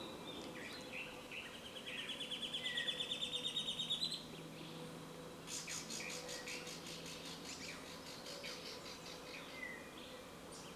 Ticotico Grande (Dendroma rufa)
Nombre en inglés: Buff-fronted Foliage-gleaner
Fase de la vida: Adulto
Localidad o área protegida: Parque Provincial Urugua-í
Condición: Silvestre
Certeza: Fotografiada, Vocalización Grabada